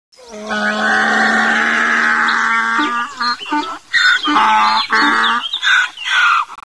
c_donkey_dead.wav